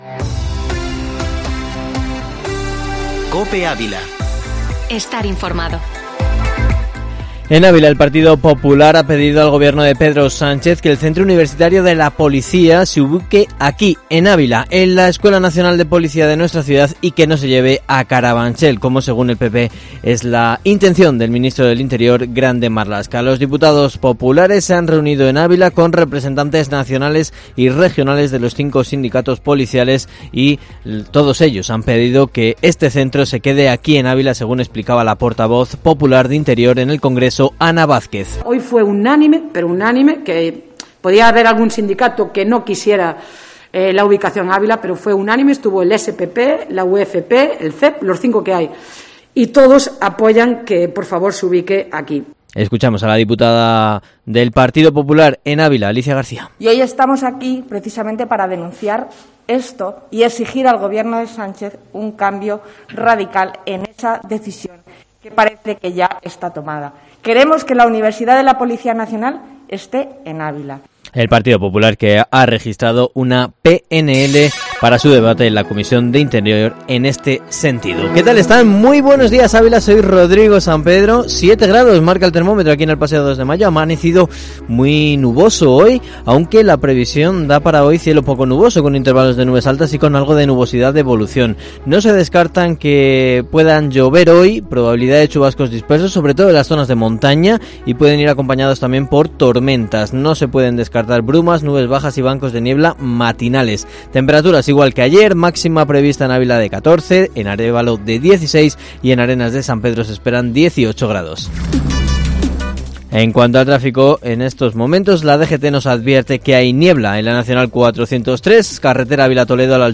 Informativo matinal Herrera en COPE Ávila 04/02/2021